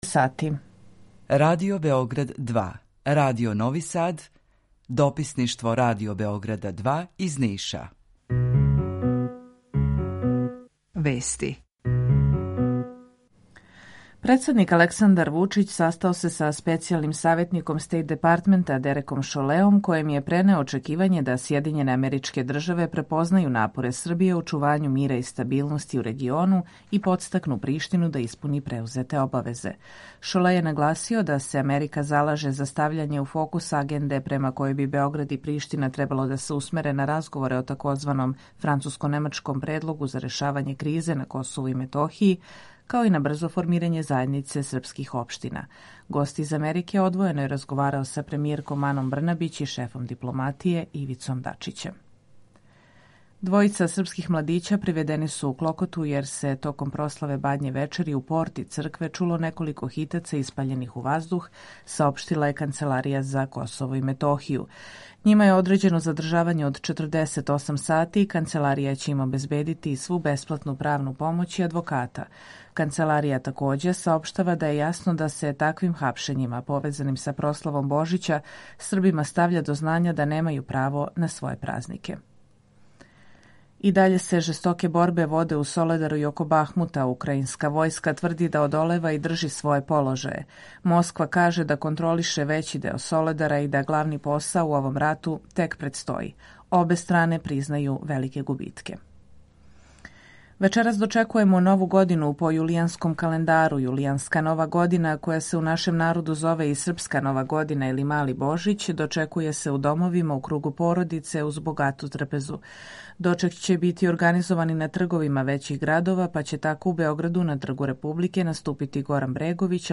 Емисију реализујемо уживо заједно са Радиом Републике Српске у Бањалуци и Радио Новим Садом
У два сата, ту је и добра музика, другачија у односу на остале радио-станице.